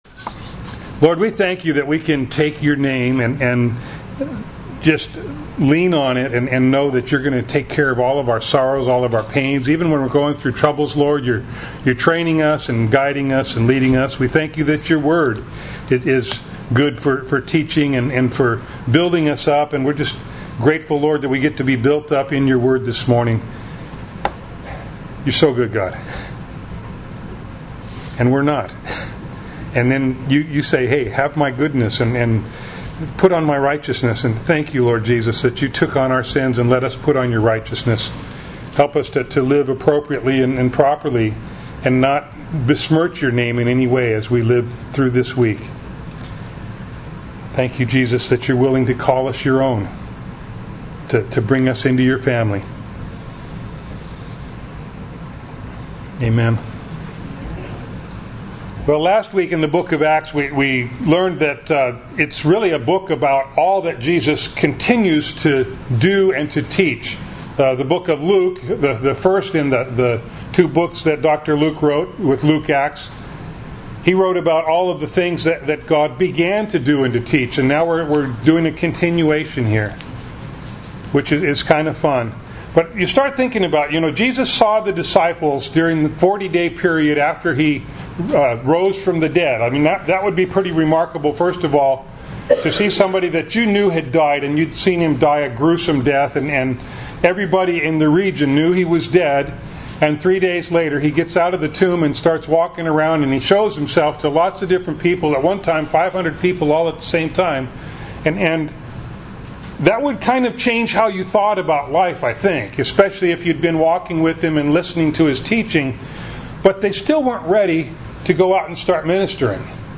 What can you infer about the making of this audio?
Acts 1:12-26 Service Type: Sunday Morning Bible Text